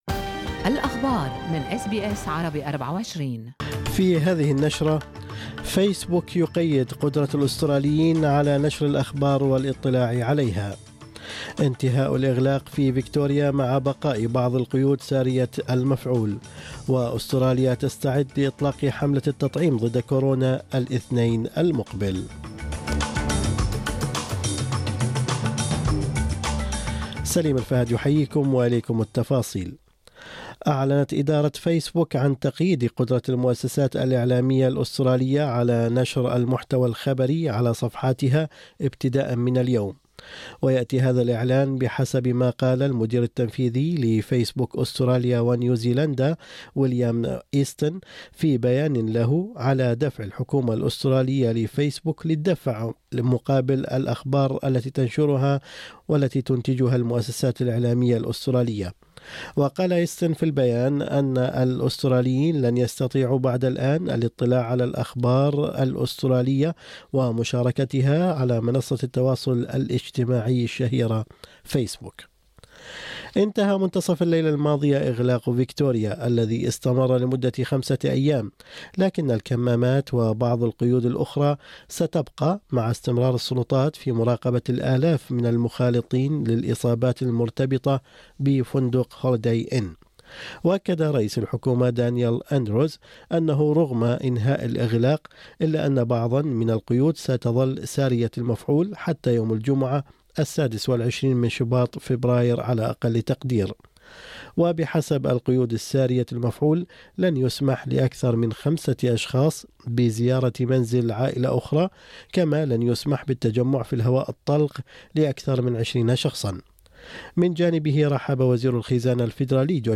نشرة أخبار الصباح 18/2/2021